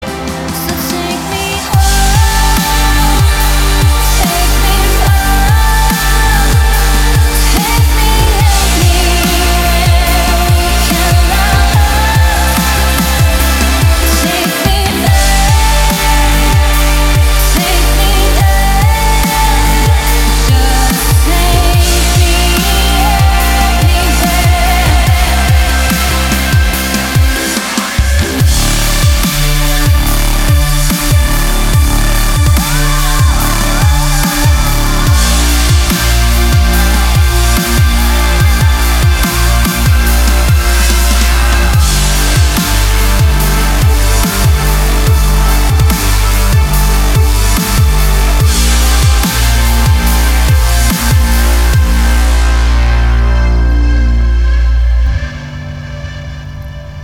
громкие
женский вокал
Electronic
Melodic dubstep